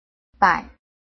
拼音查詢：【詔安腔】bai ~請點選不同聲調拼音聽聽看!(例字漢字部分屬參考性質)